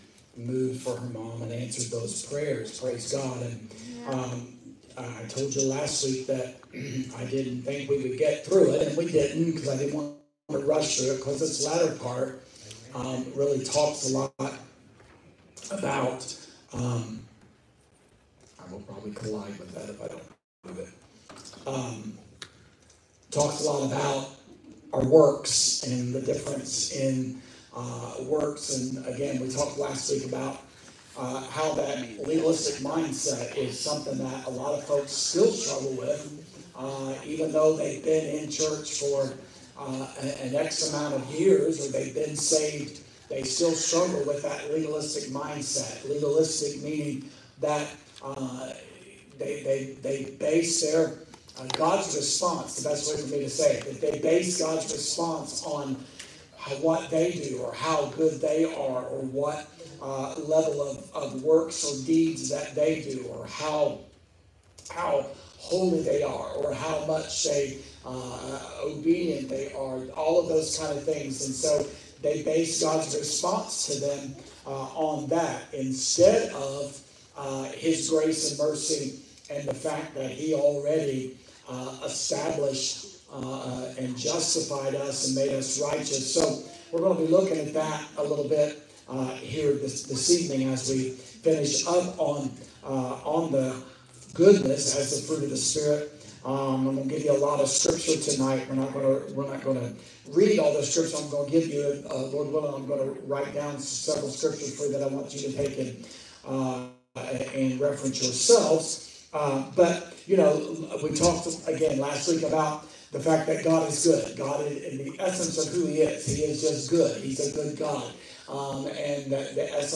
Sermons | Judah Tabernacle